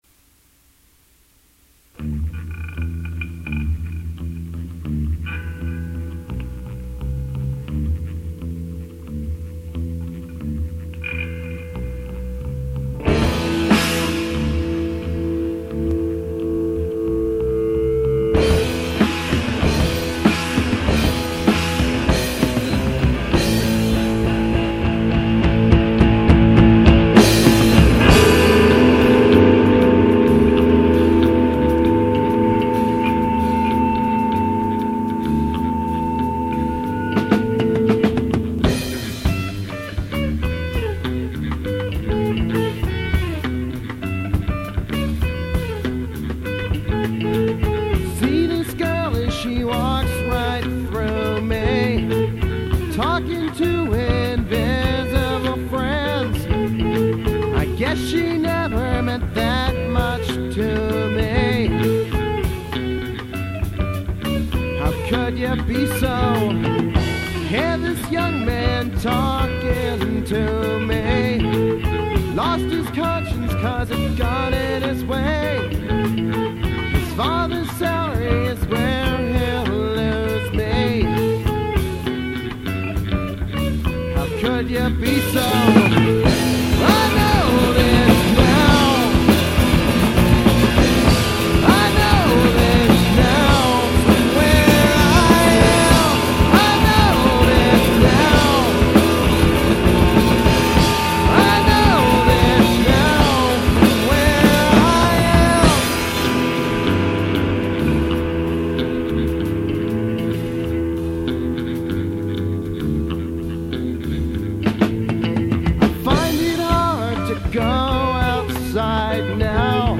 The sound quality isn't perfect, coming from analog tape and all, but it works.
This is, however, the studio version; there are live versions that are much better.
If that's too draggy and full of early-20s angst for you, try "Rant," a delightful bit of idiocy.